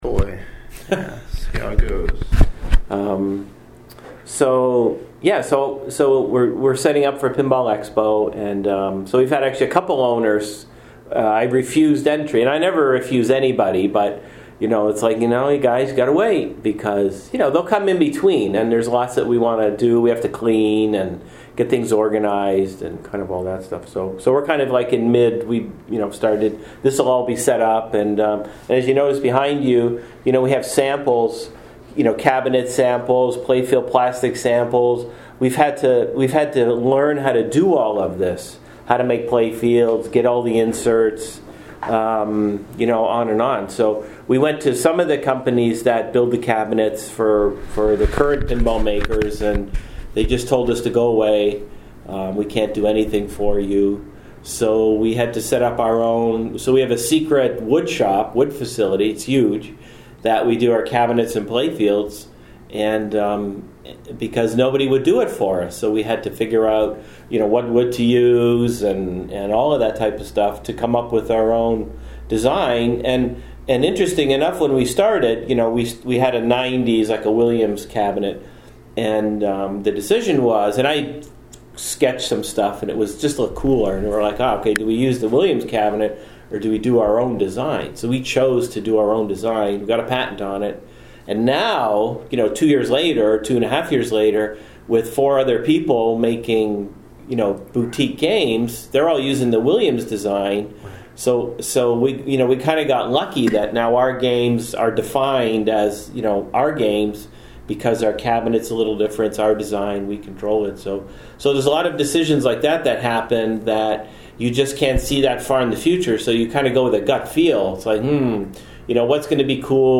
904 PINBALL ZINE NEWS - MP3 & Video Interviews with Pinball Designers, CEOs, Operators and Artists.